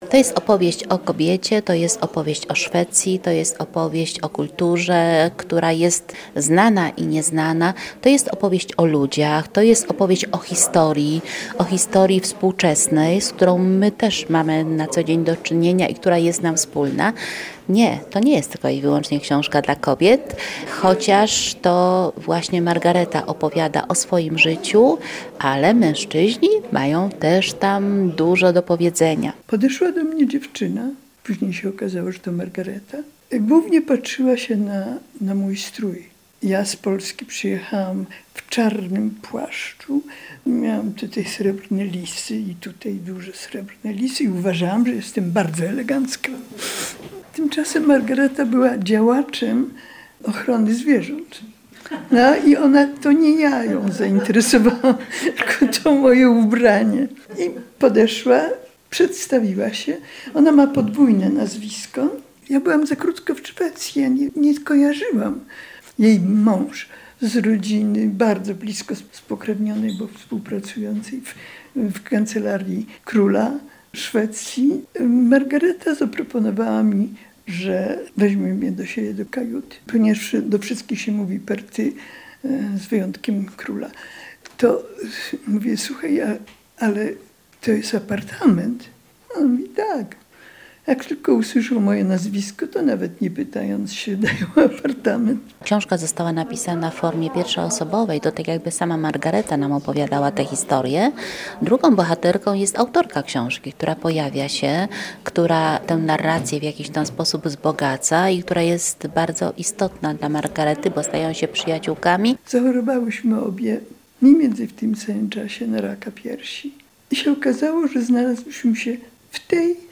Na spotkaniu autorskim wokół książki „Maragreta”